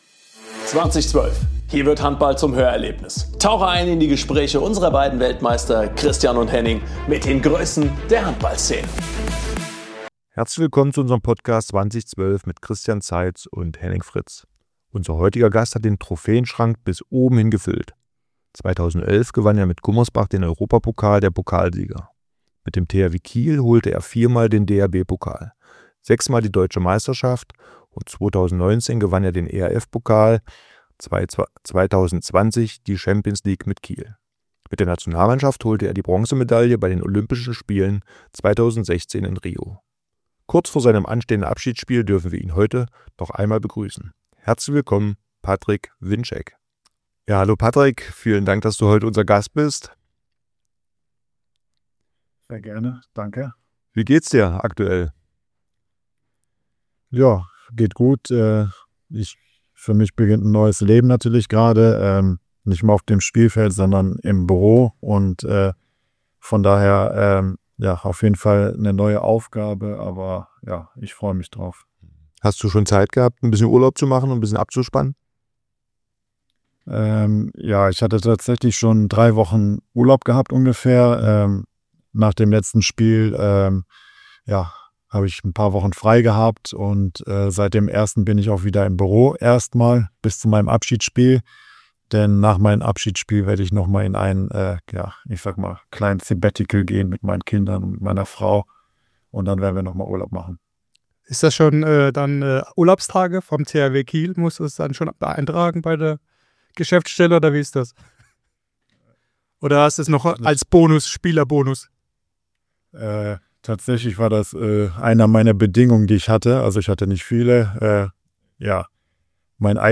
Die Gastgeber sprechen mit dem Ausnahme-Kreisläufer über diese gemeinsame Zeit, die Höhepunkte seiner Karriere, seine Pläne nach der aktiven Laufbahn und natürlich über sein großes Abschiedsspiel “Ahoi, Patrick!” – und vieles mehr.